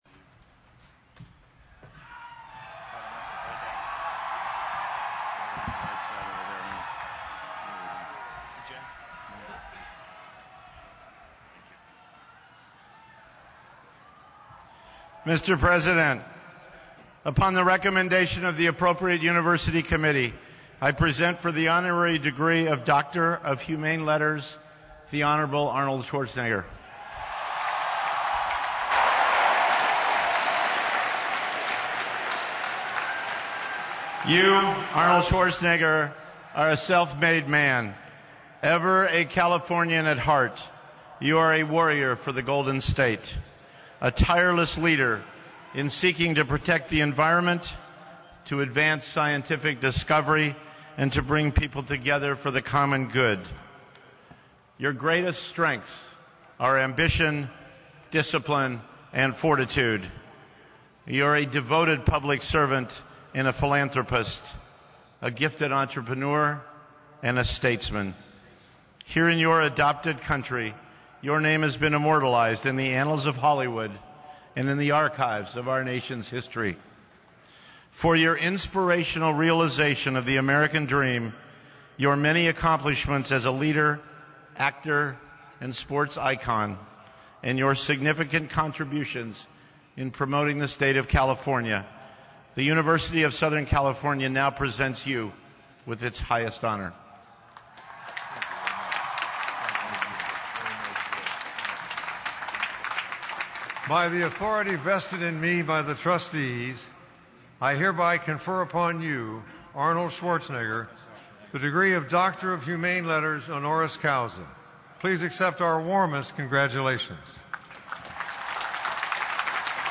Governor Schwarzenegger gave the commencement speech and also received an honorary doctorate degree.